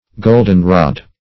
goldenrod \gold"en*rod`\, golden-rod